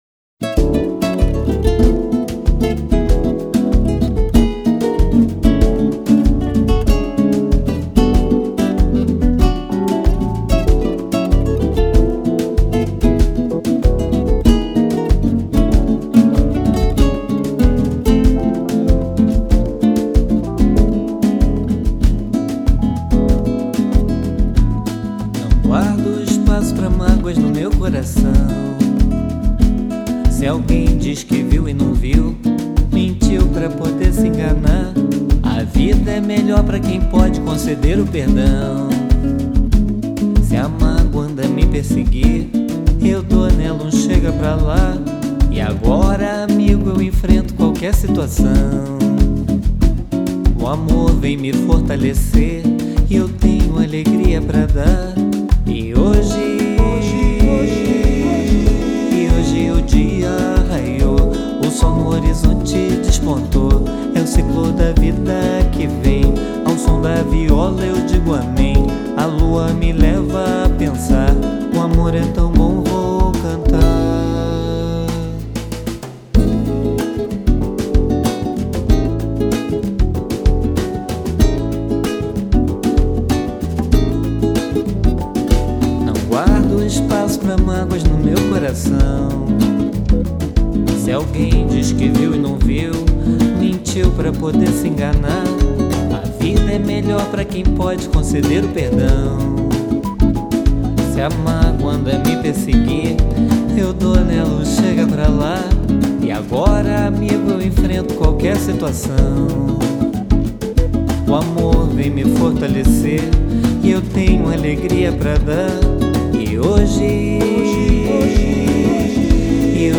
samba medio Samba Dolente